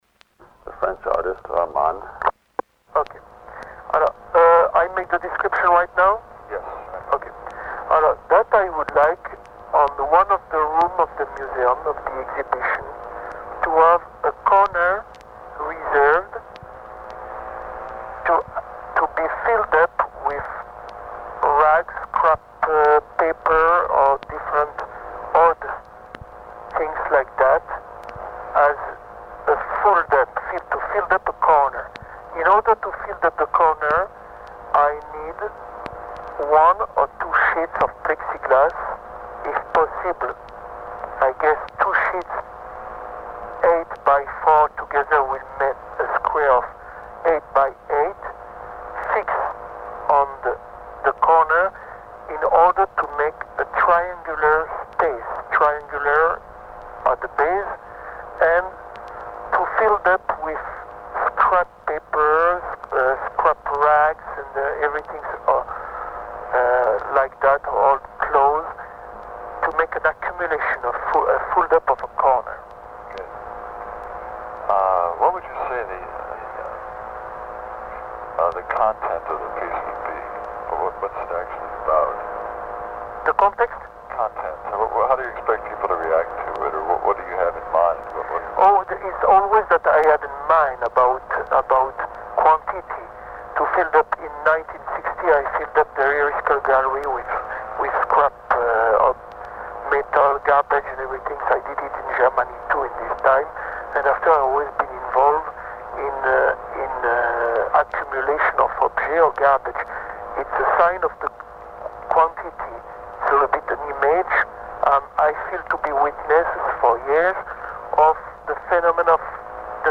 audio extrait du vinyle de 1969